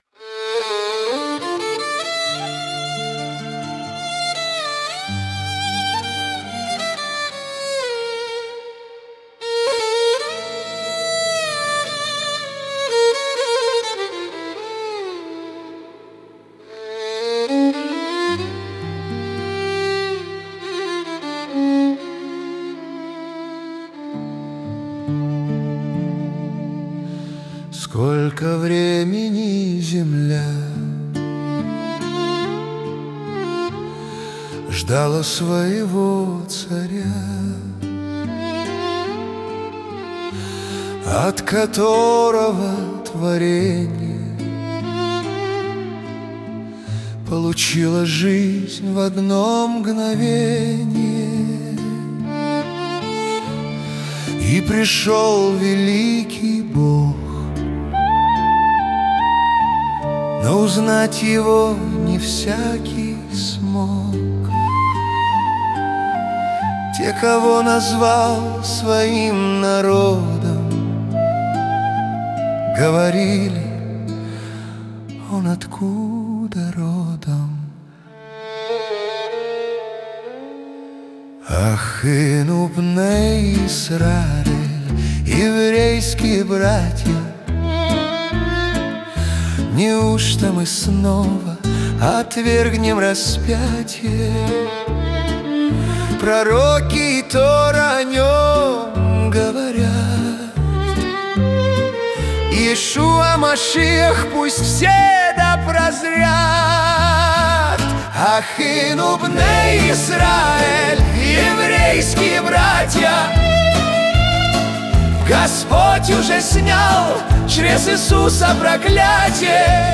134 просмотра 490 прослушиваний 45 скачиваний BPM: 71